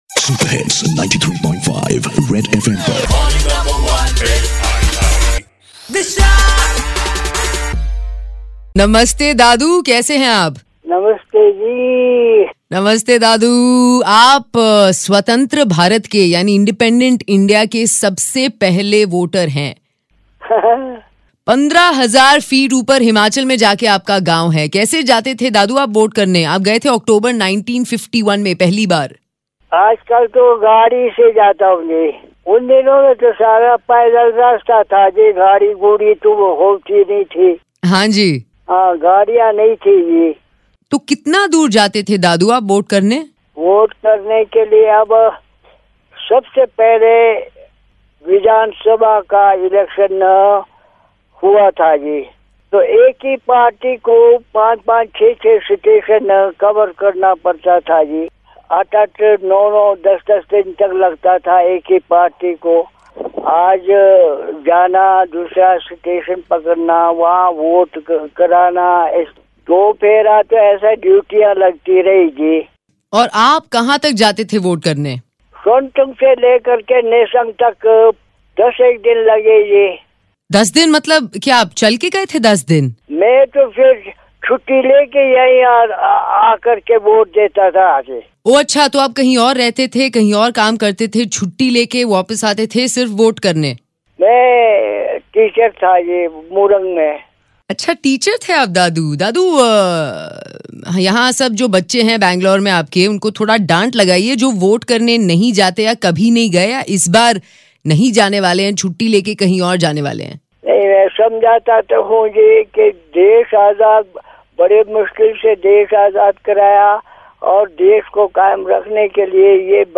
Interview with the 1st person to vote after Independence,Shyam Sundar Negi